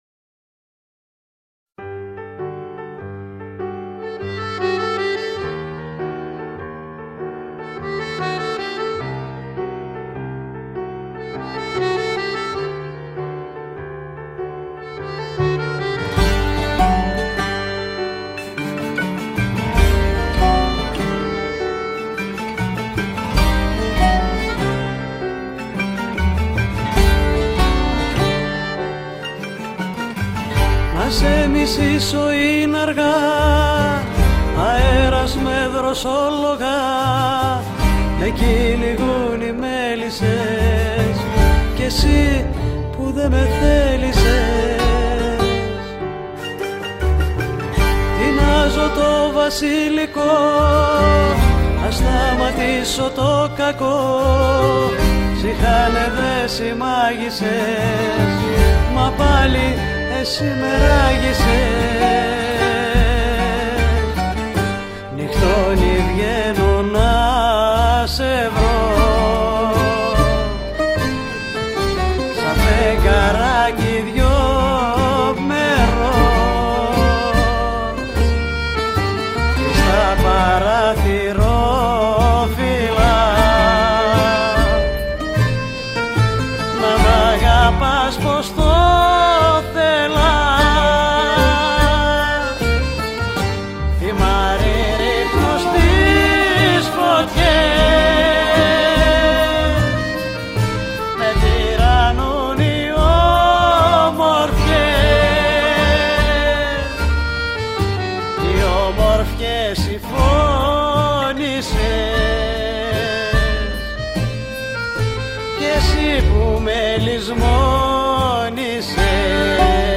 Με τη δωρική φωνή της συγκινεί όσο λίγοι ερμηνευτές.